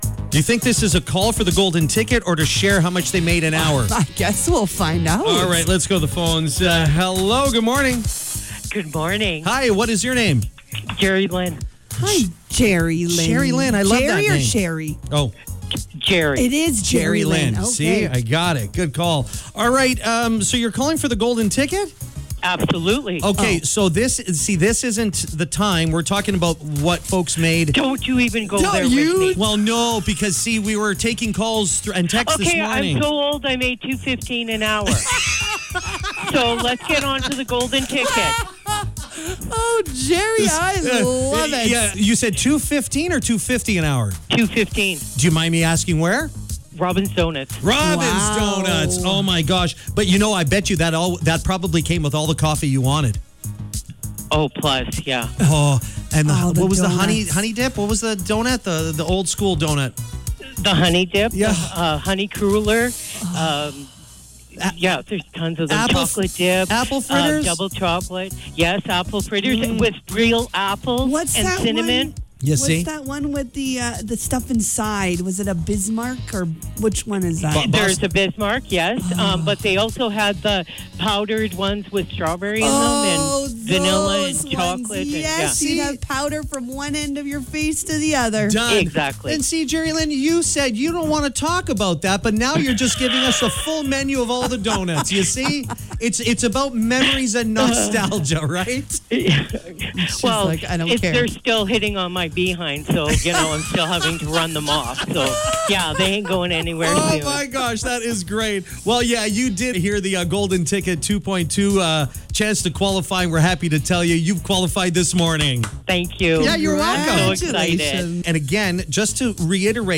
It was a fun call: